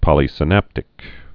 (pŏlē-sĭ-năptĭk)